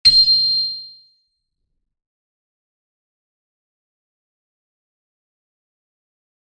piano-sounds-dev
c7.mp3